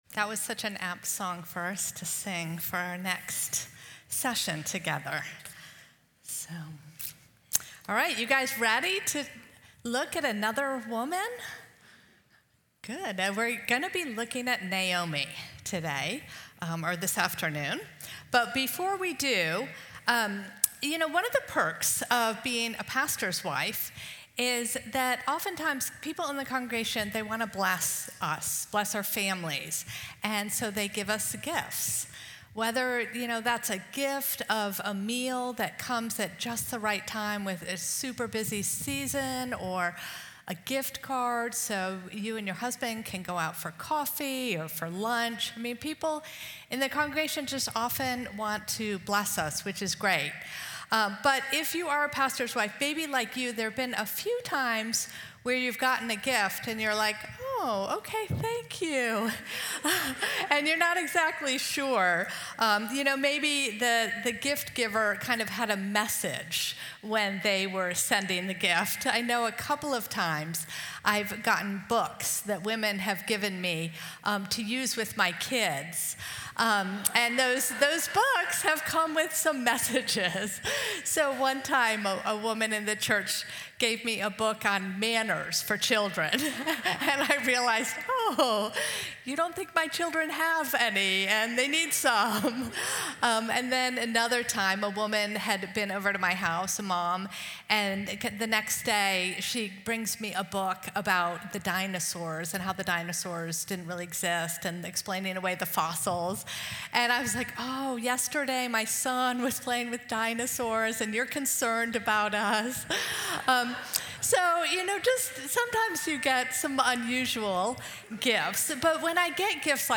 Audio recorded at Feed My Sheep for Pastors Wives Conference 2024.